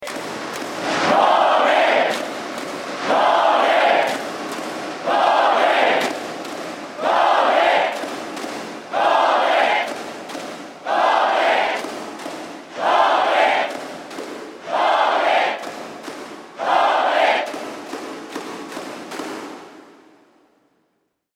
2022-2023シーズンの応援歌
太鼓：ドン・ドン（GO！VIC！）
※クラップは続くよどこまでも